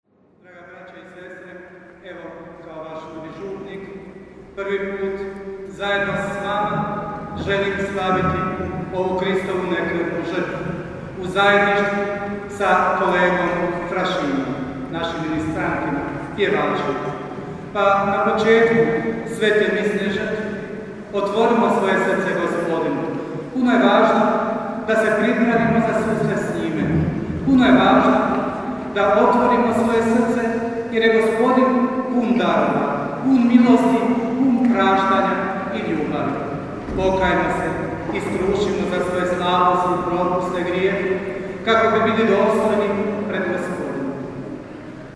UVOD u sv. MISU: